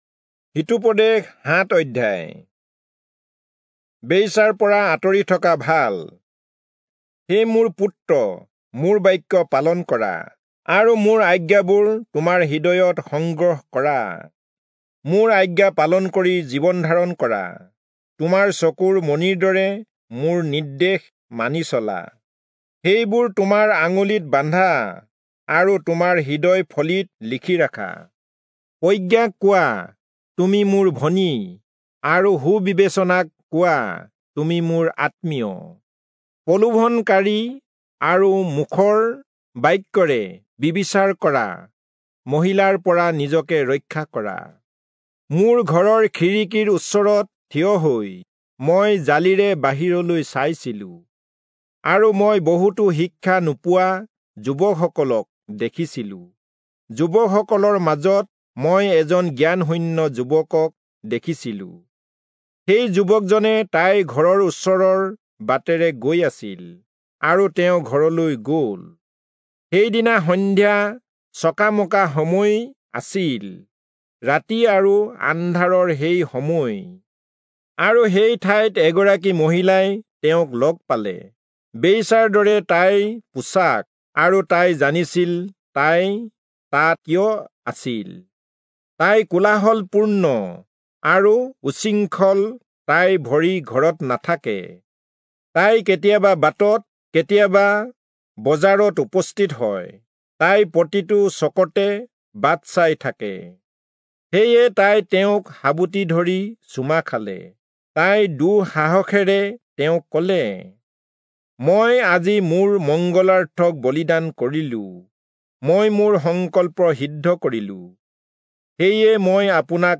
Assamese Audio Bible - Proverbs 19 in Erven bible version